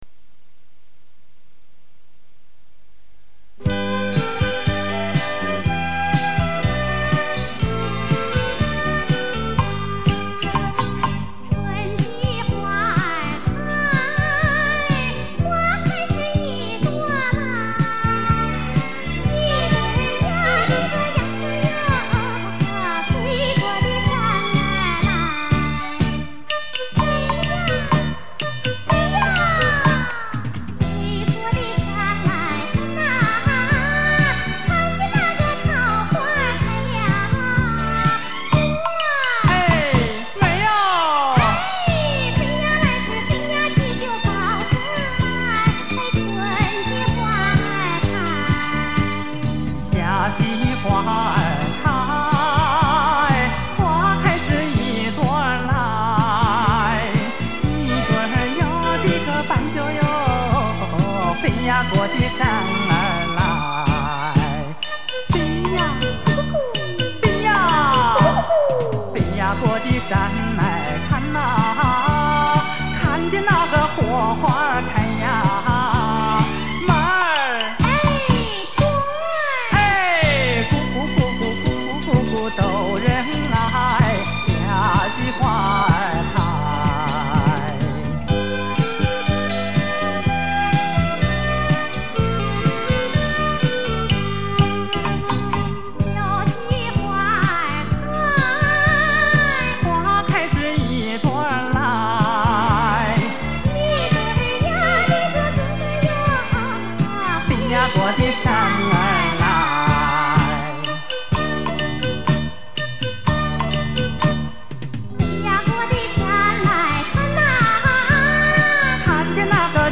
她的演唱感情真挚，亲切动人，富有意境。